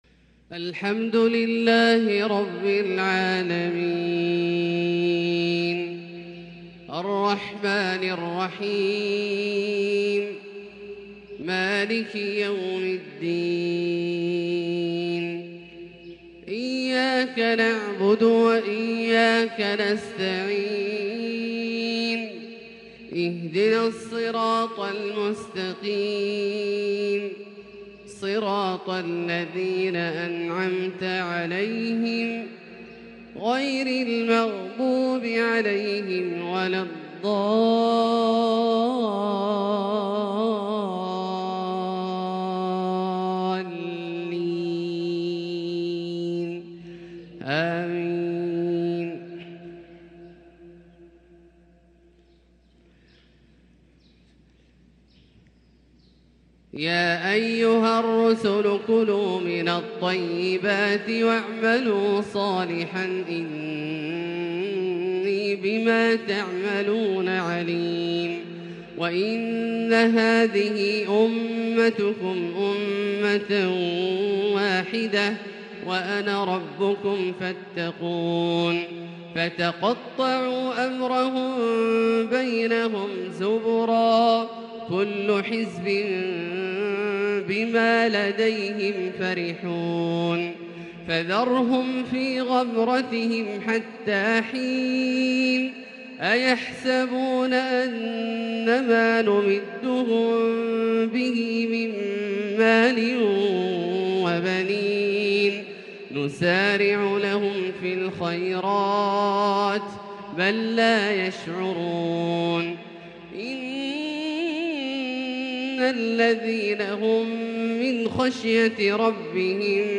فجر الأربعاء 6-8-1443هـ من سورة المؤمنون | Fajr prayer from Surat Al-mu’menoon 9-3-2022 > 1443 🕋 > الفروض - تلاوات الحرمين